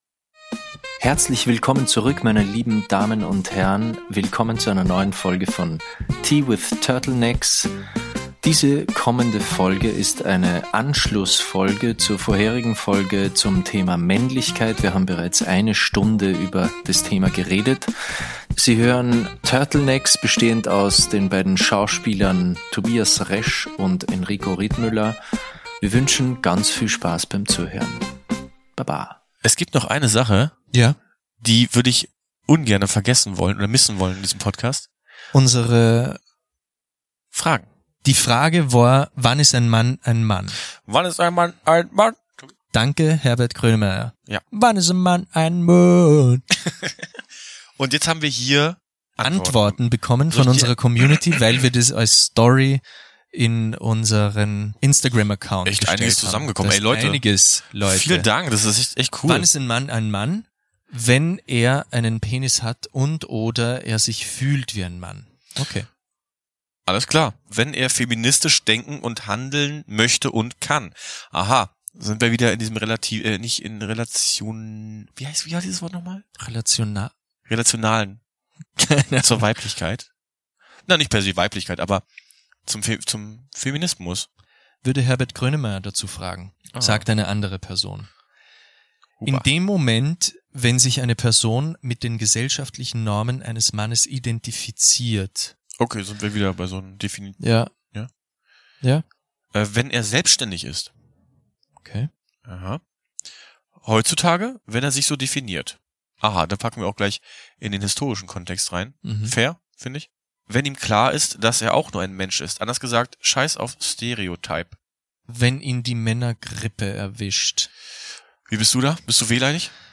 Sorry... sorry, wir haben uns total verquatscht. Bei dem Thema gab es einfach viel zu viel tea zu spillen, aber dafür erwartet euch in dieser Episode ein geniales Hörspiel bei dem wir Jason, Chloe, Stepdad Joe und Cheese Mcmayer einmal genauer kennenlernen.